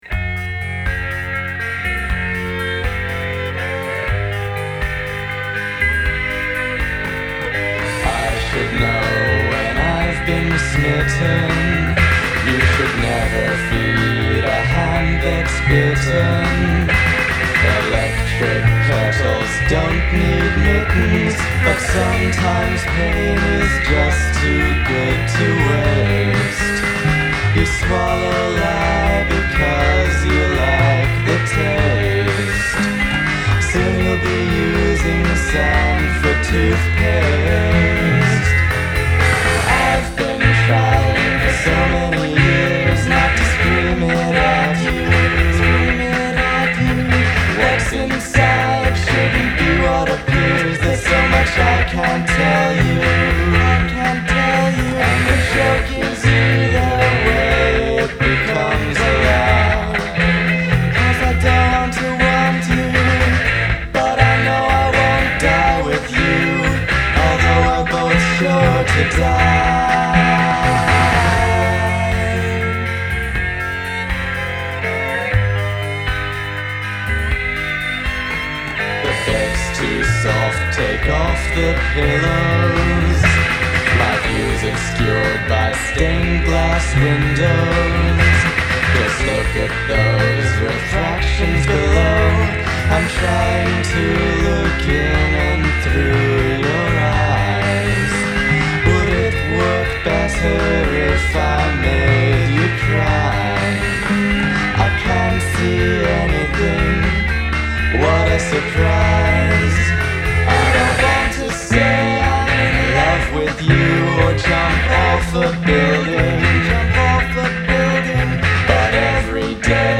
4-track